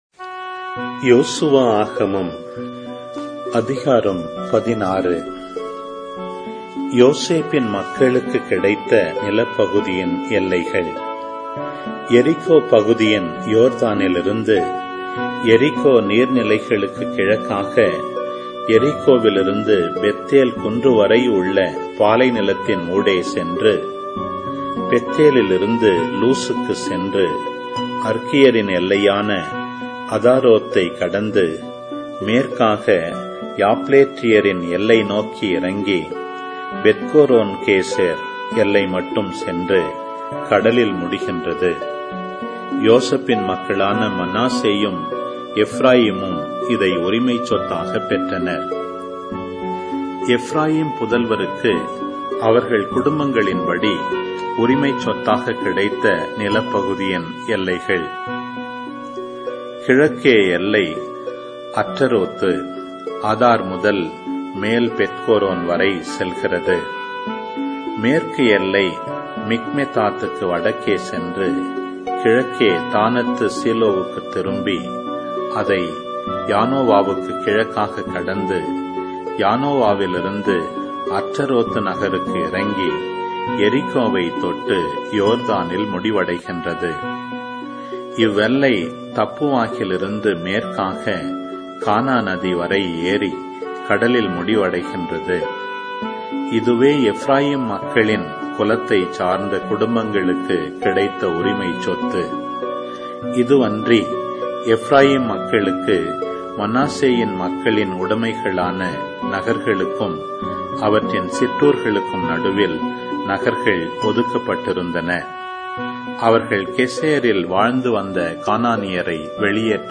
Audio Bible